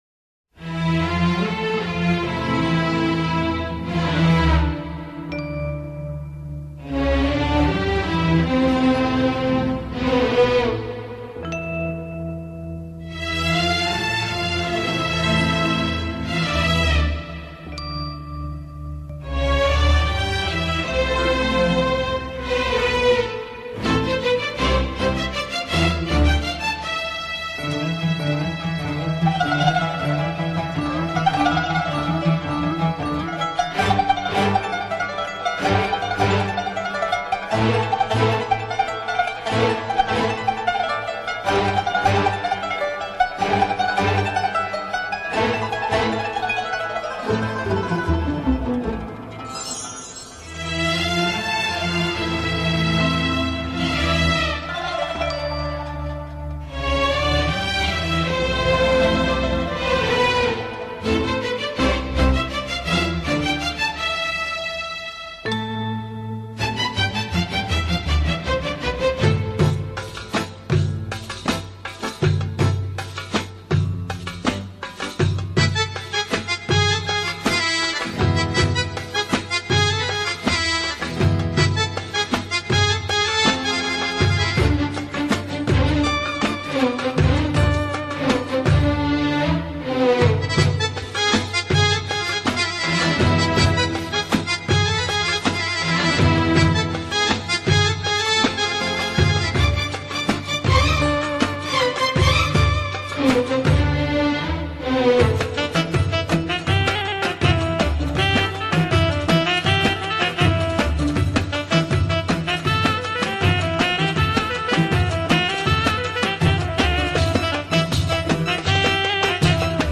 klasickou orientální skladbu